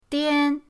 dian1.mp3